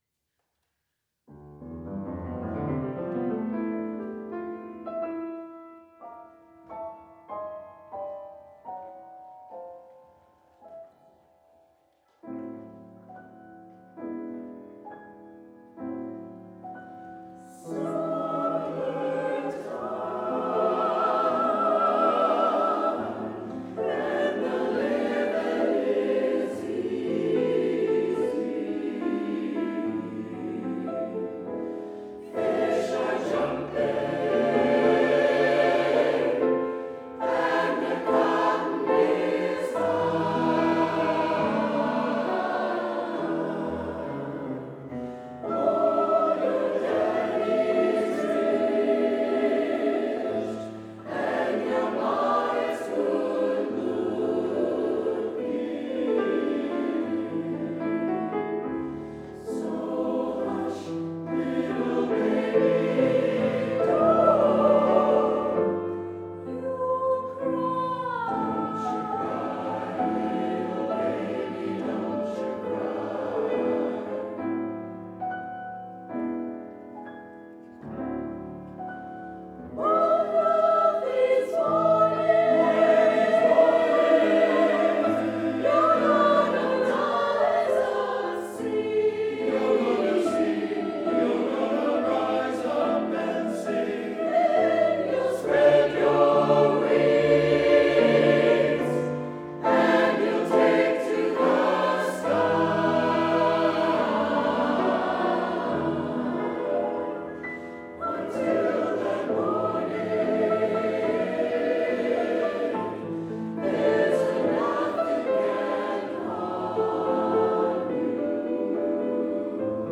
We work for The Boeing Company, and we sing for our friends!
Choir